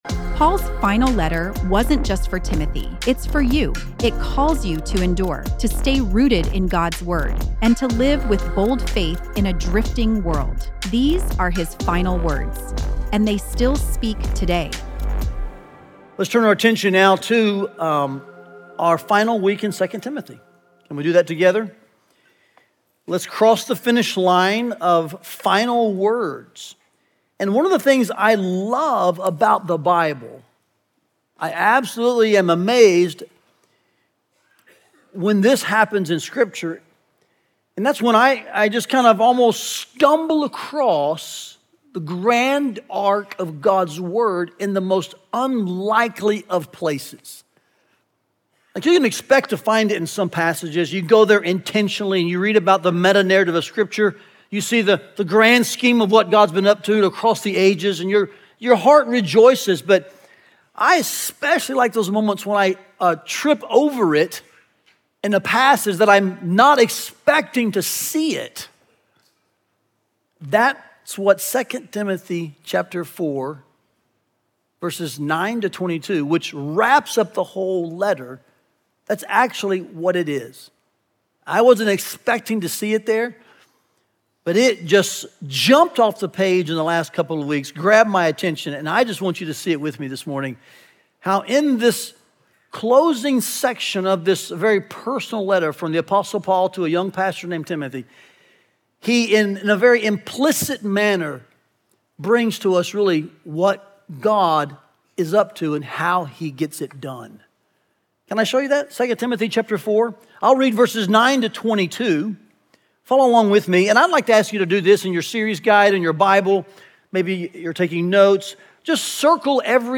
Listen to the final sermon from our 2 Timothy series, “Final Words”, and learn more about the series here.